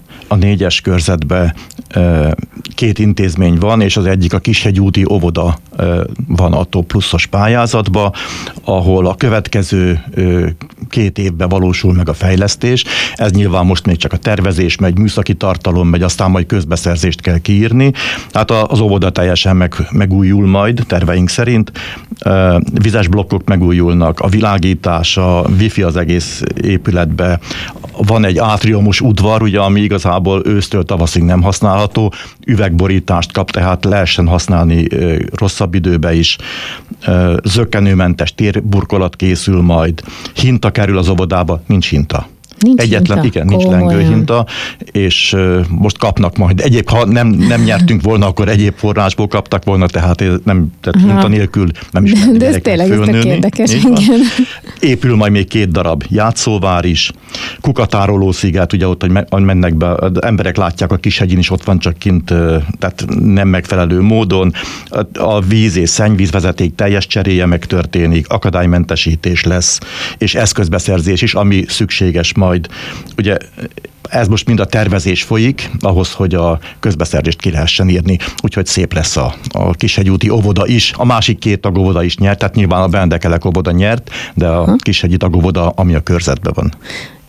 Teljesen megújul a Kishegyi úti óvoda TOP Pluszos pályázati pénzből mondta el rádiónknak Mezősi Árpád a 4-es választókerület önkormányzati képviselője szeptember 18-án csütörtökön. A képviselő részletesen beszélt arról, hogy milyen fejlesztések várhatóak az elnyert Európai Uniós forrás terhére.